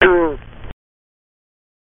Sitting by the camp fire tonight we’re enjoying all the sounds you hear at camp! Often you can not see these animals but you can identify them by their sounds.